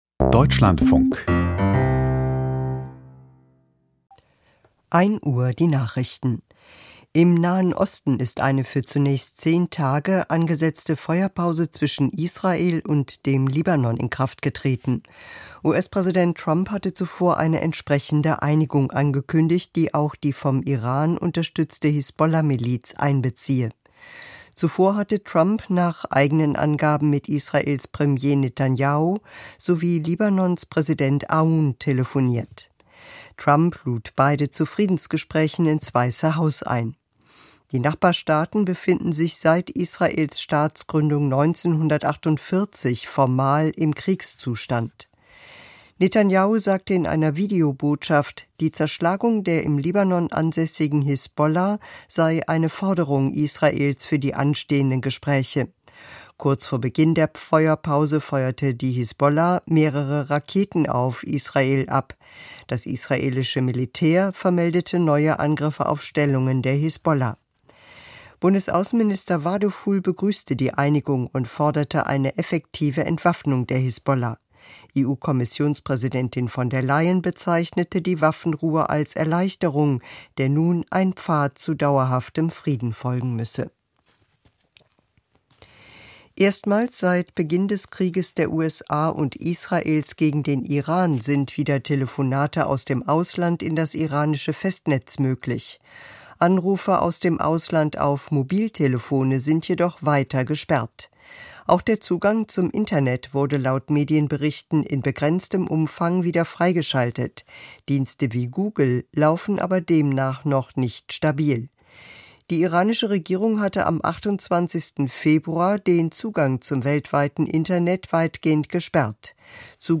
Die Nachrichten vom 17.04.2026, 01:00 Uhr